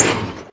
Sound / Minecraft / mob / blaze / hit4.ogg